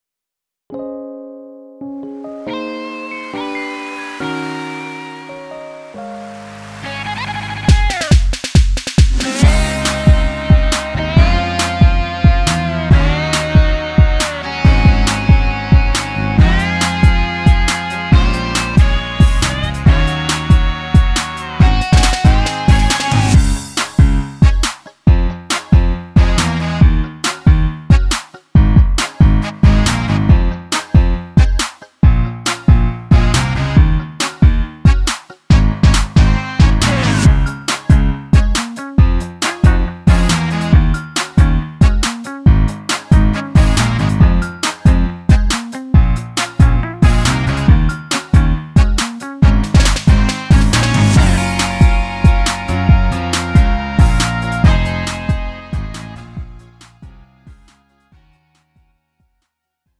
음정 원키 3:44
장르 가요 구분 Lite MR